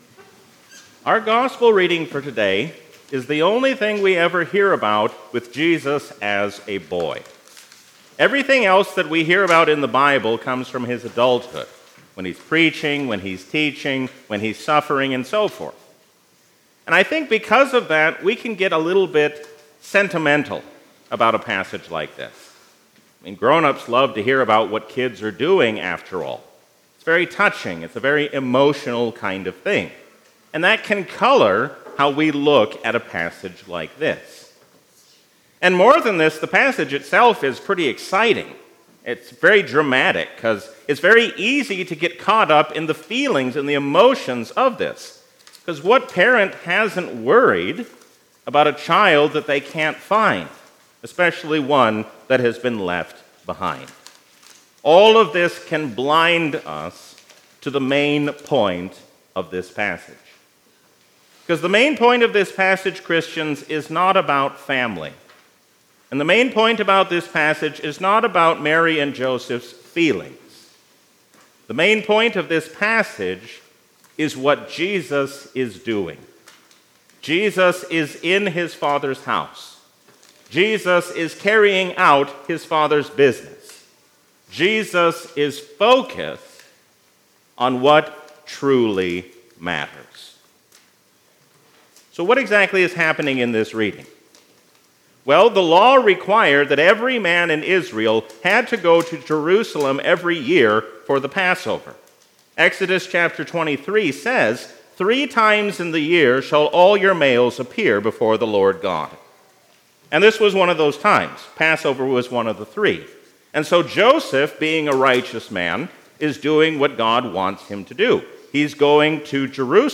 A sermon from the season "Epiphany 2023." God wants us to use His name for good and not for evil.